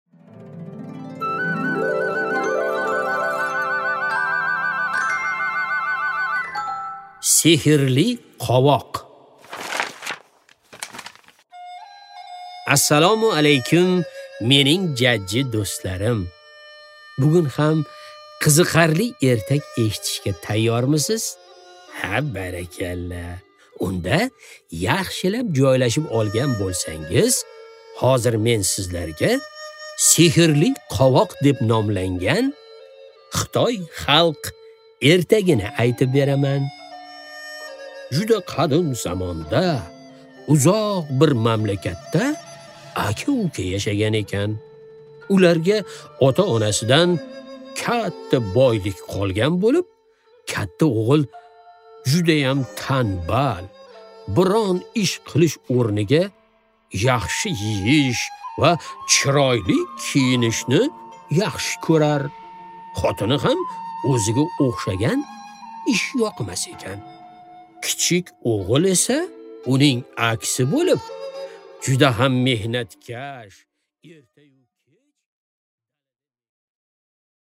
Аудиокнига Sehrli qovoq